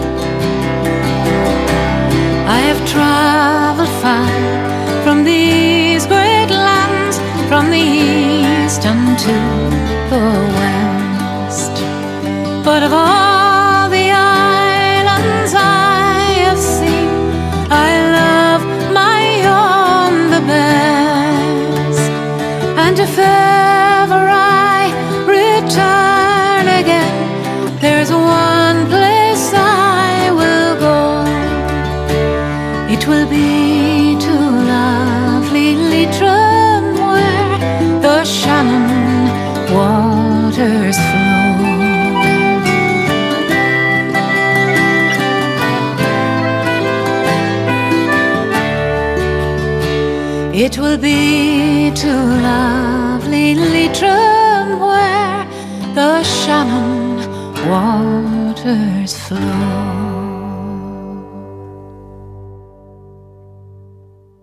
Backing Vocals
Bodhrán
Piano
the album is a collection of 13 eclectic songs and tunes.